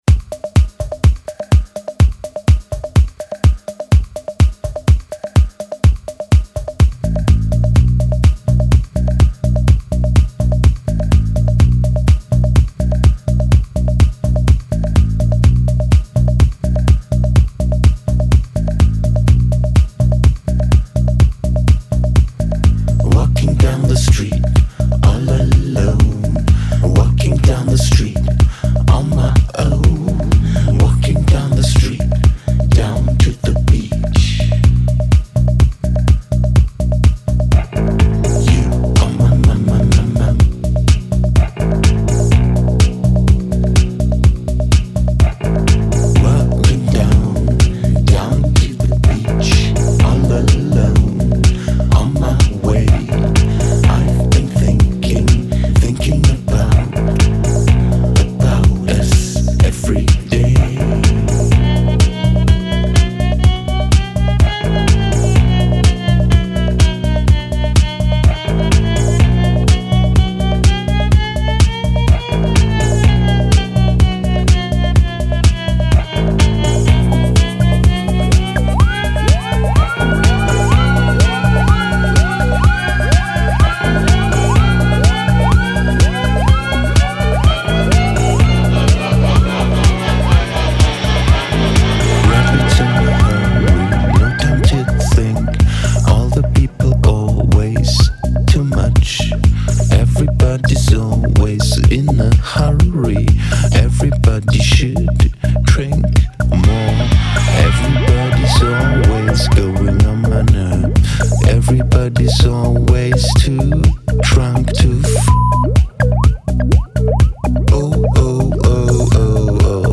lurking, nasal delivery